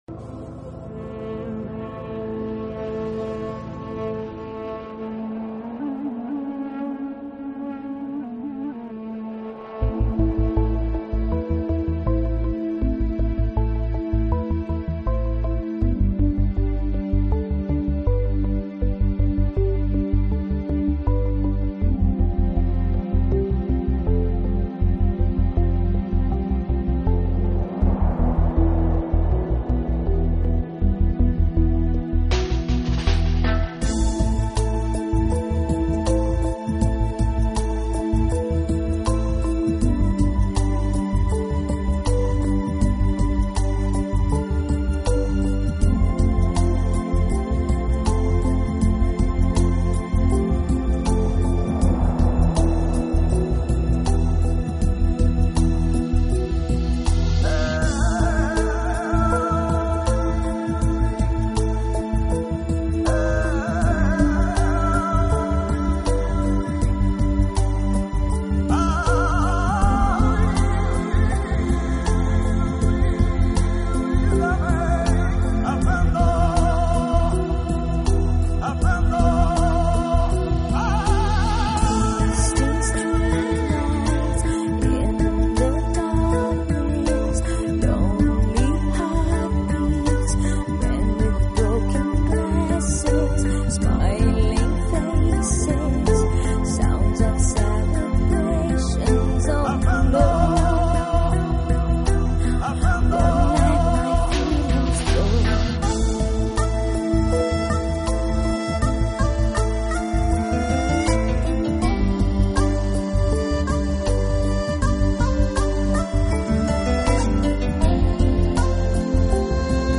Genre: New Age / Enigmatic / Pop